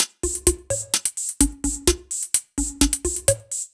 Index of /musicradar/french-house-chillout-samples/128bpm/Beats
FHC_BeatD_128-03_Tops.wav